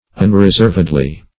Unreserved \Un`re*served"\, a.